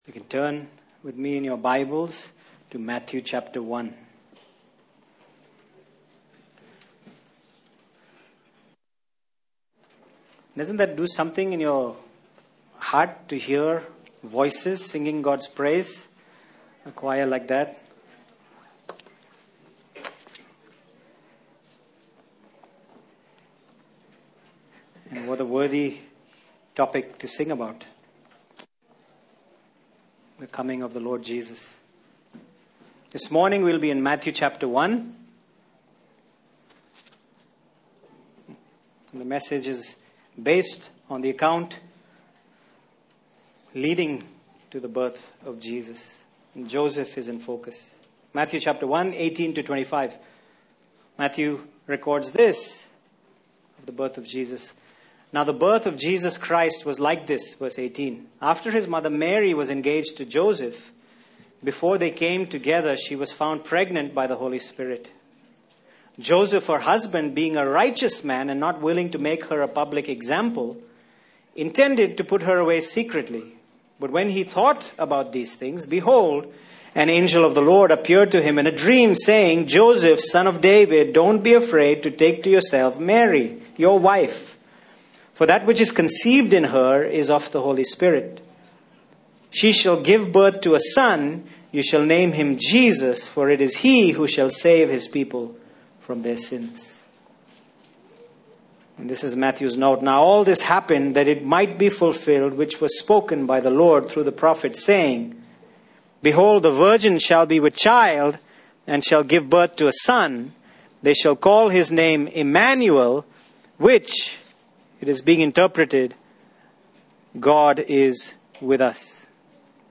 Passage: Matthew 1:18-25 Service Type: Sunday Morning